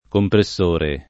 [ kompre SS1 re ]